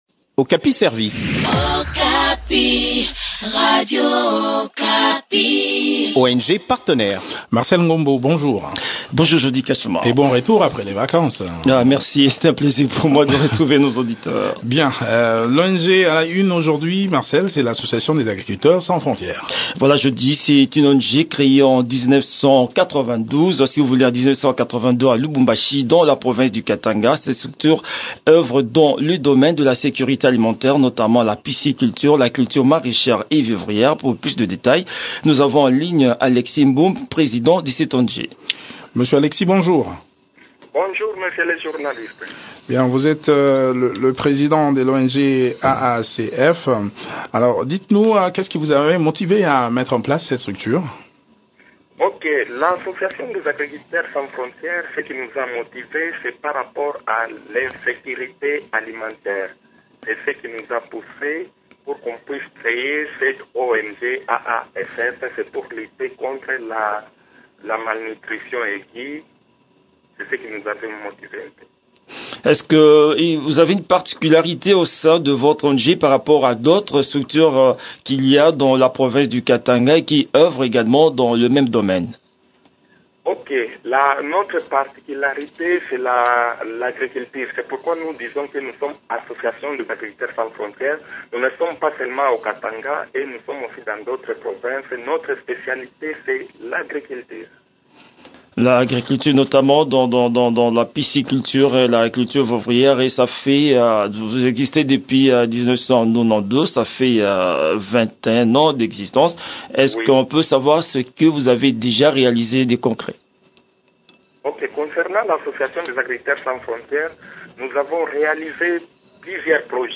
Le point des activités de cette structure dans cet entretien